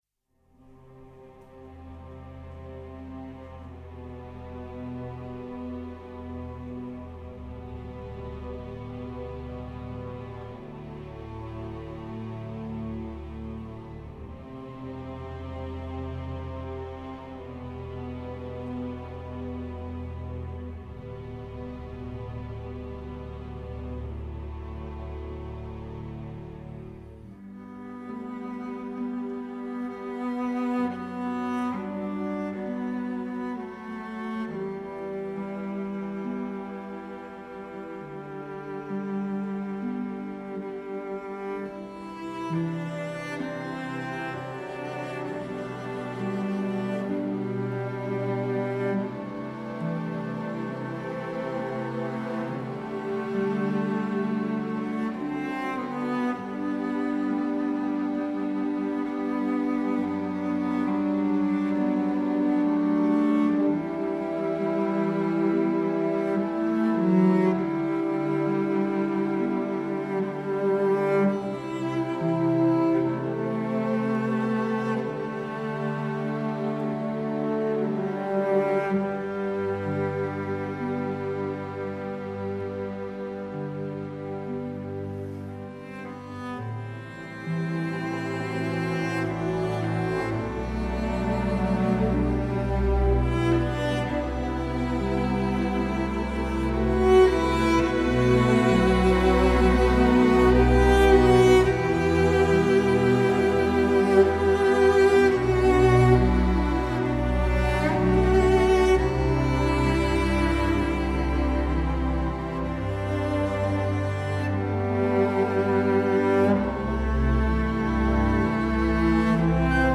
a harrowing, incredibly emotive score
Recorded at Abbey Road Studios